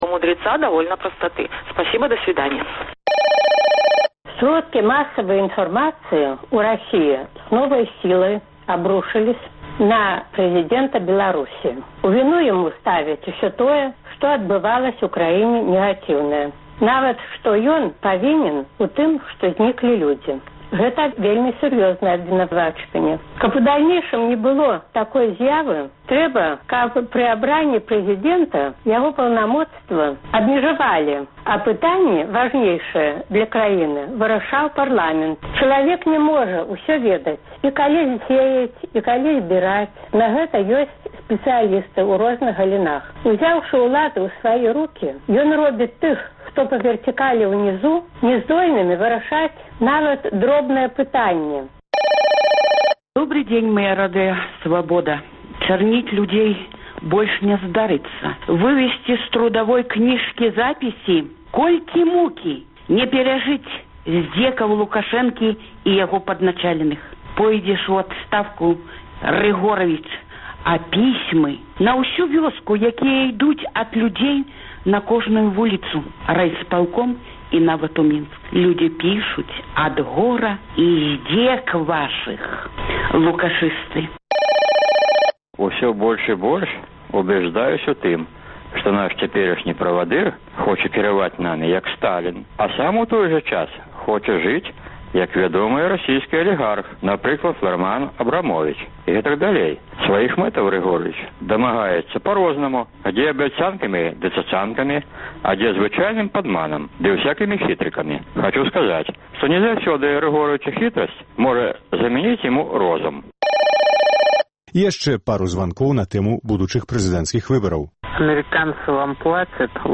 Агляд тэлефанаваньняў за тыдзень